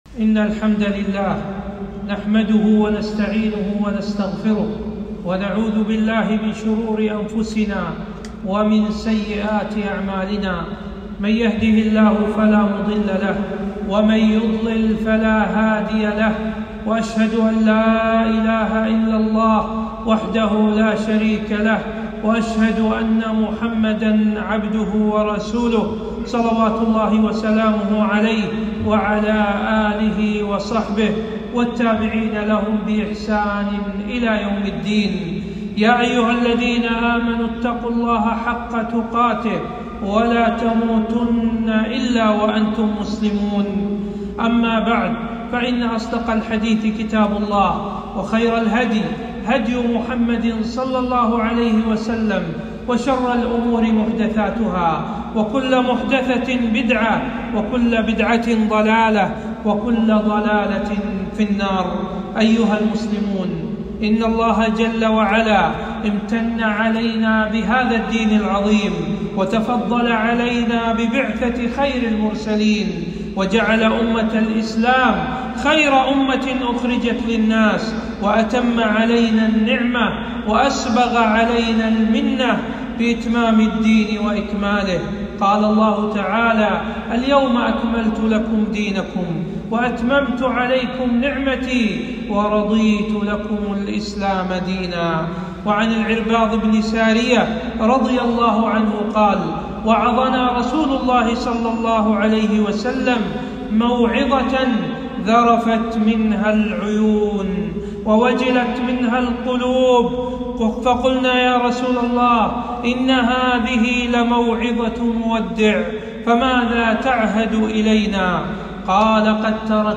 خطبة - طاعة ولي الأمر بالمعروف